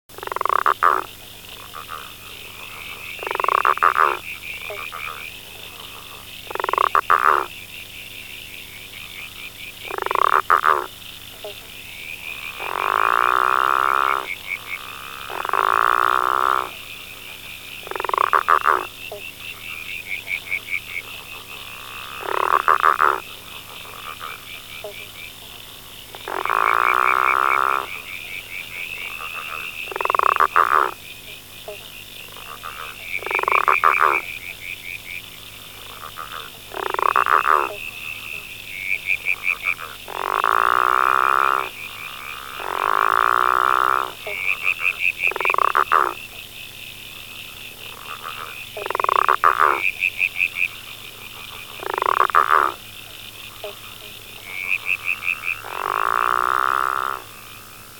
Sounds of a Growling Grass Frog:
Litoria-raniformis_-Growling-Grass-Frog.wma